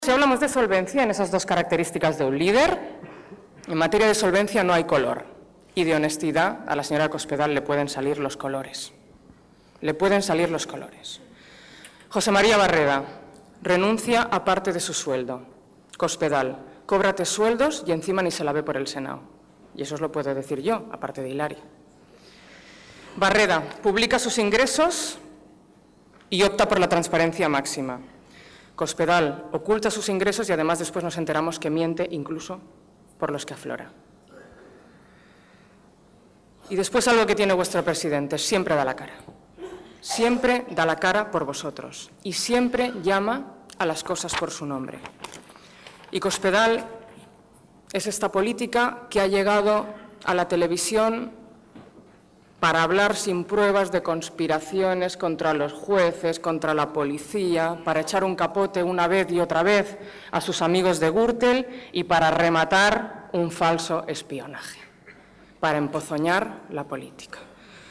En el acto de celebración del centenario de la Agrupación de Puertollano